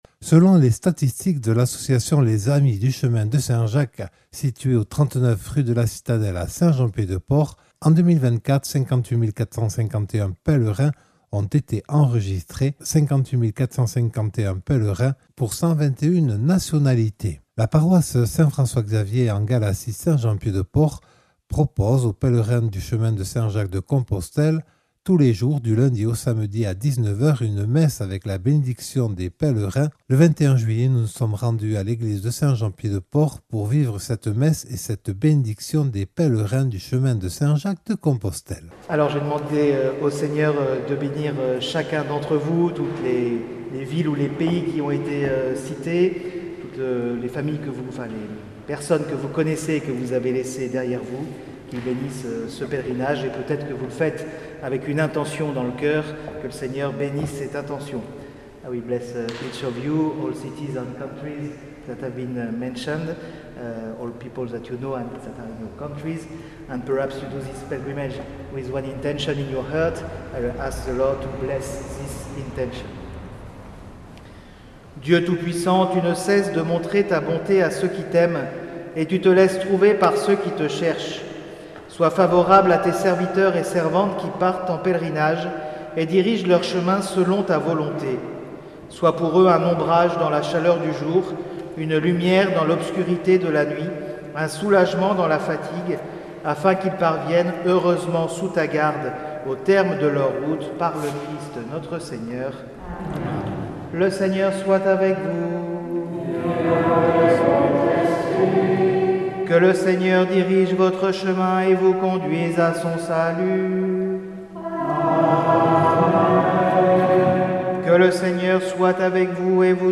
A Saint Jean Pied de Port.